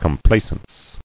[com·pla·cence]